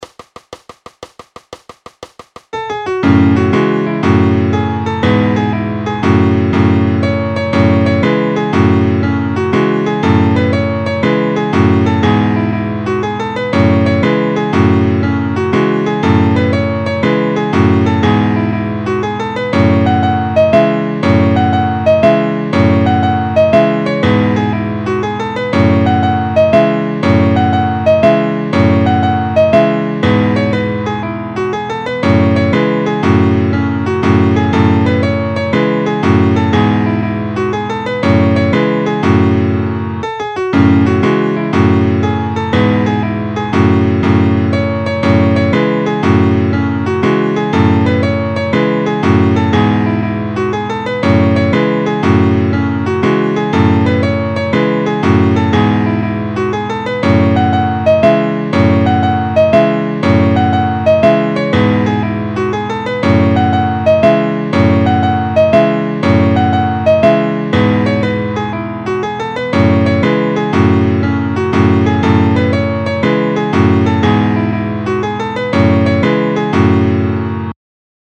Noty na snadný klavír.
Formát Klavírní album
Hudební žánr Irská lidová hudba